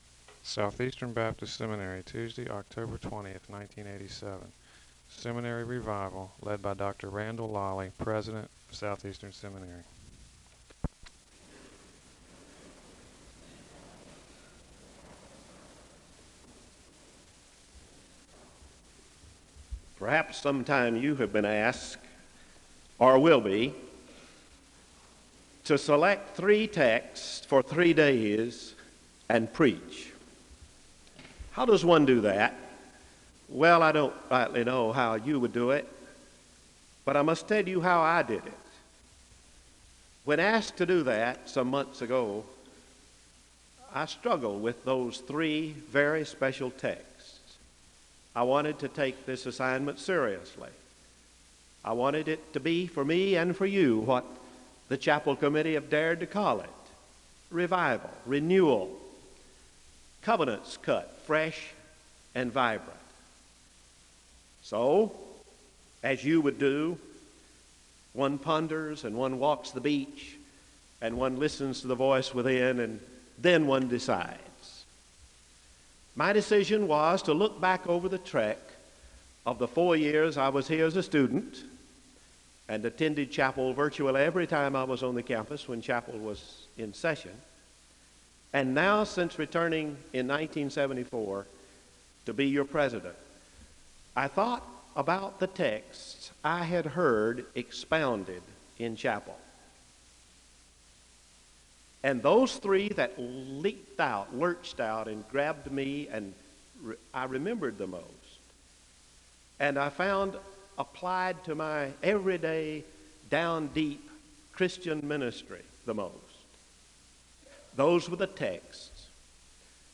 There is a moment of prayer (3:42-5:07). The congregation joins in singing “Blessed Assurance” (5:08-7:19).